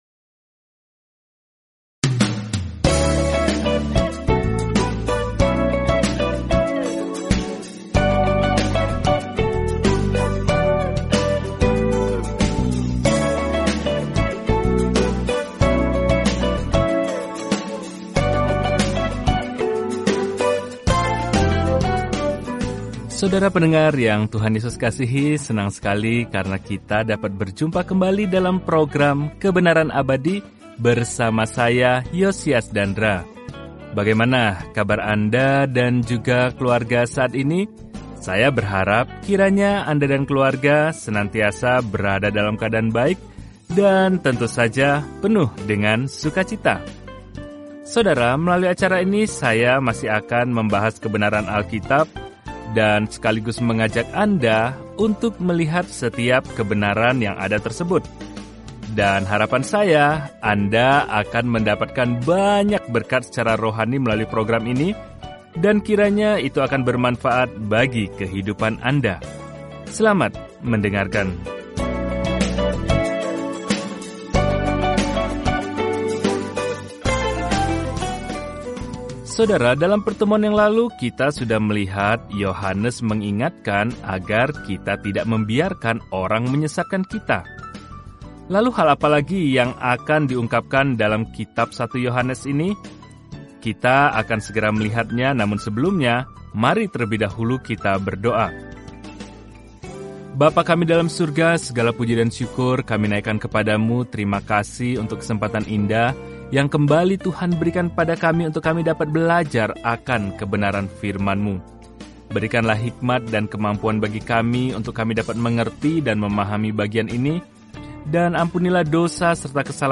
Firman Tuhan, Alkitab 1 Yohanes 3:8-10 Hari 14 Mulai Rencana ini Hari 16 Tentang Rencana ini Tidak ada jalan tengah dalam surat pertama Yohanes ini – kita memilih terang atau gelap, kebenaran daripada kebohongan, cinta atau benci; kita menganut salah satunya, sama seperti kita percaya atau menyangkal Tuhan Yesus Kristus. Telusuri 1 Yohanes setiap hari sambil mendengarkan pelajaran audio dan membaca ayat-ayat tertentu dari firman Tuhan.